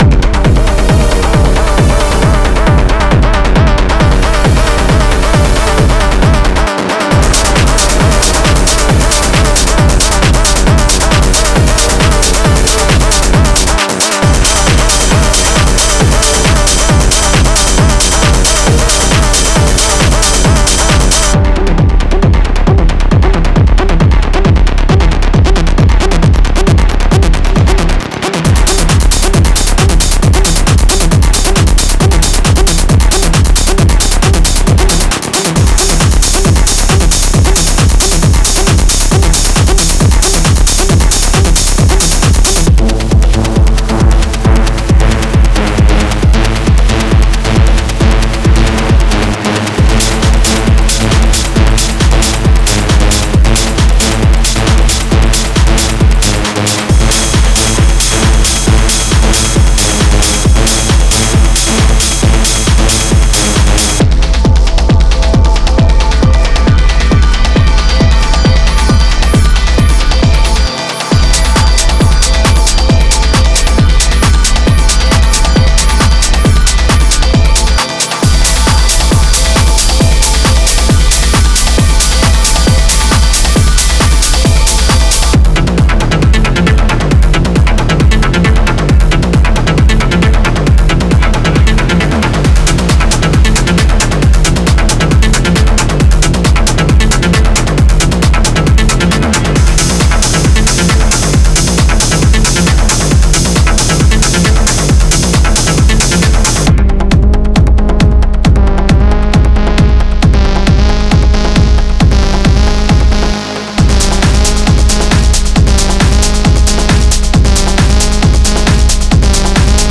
Genre:Techno
185 Drum loops (Full, Kick, Clap, Hihat, Perc, Ride)
20 Vox loops
15 Pad loops (Key labelled)